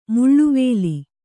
♪ muḷḷuvēli